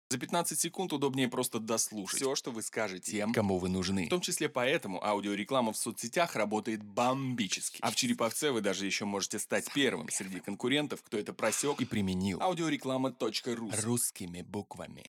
Аудиореклама